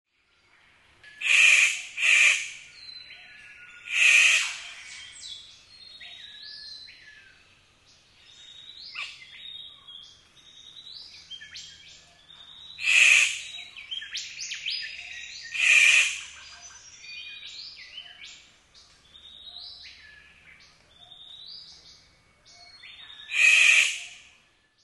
7. Victoria's Riflebird
What do I do? I dance around holding my wings out and make a loud noise that sounds like “yaas, yaas, yaas”
VictoriasRiflebird.mp3